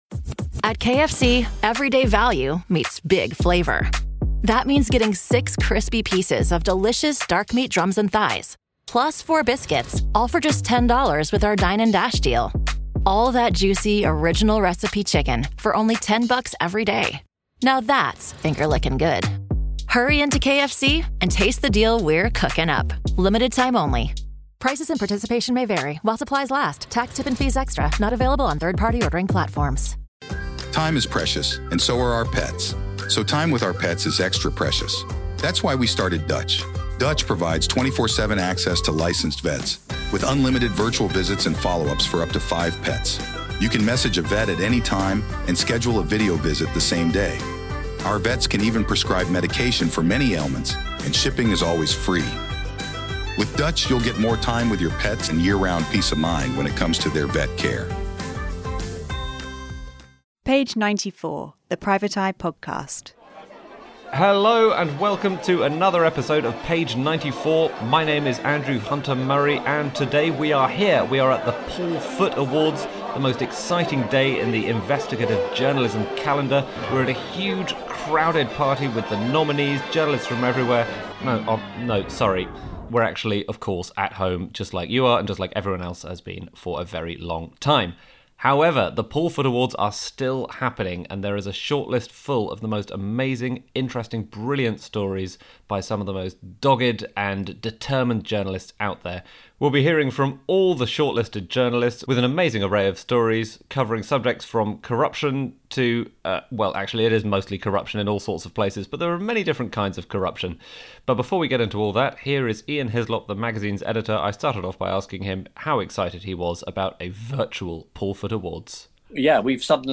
This month's podcast comes to you from the Paul Foot Awards 2020, featuring all the shortlisted entries for the best investigative journalism of the year.